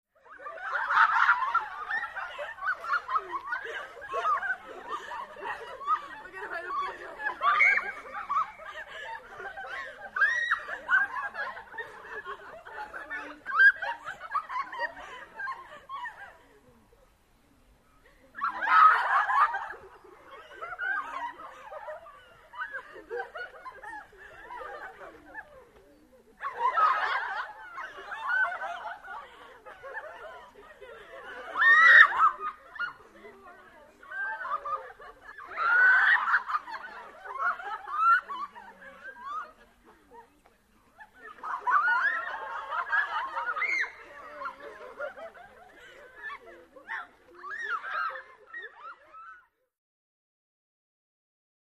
Teenage Girls Giggle And Laugh From Medium Pov, W Light Footsteps.